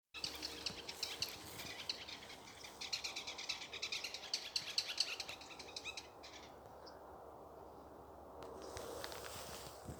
White-backed Woodpecker, Dendrocopos leucotos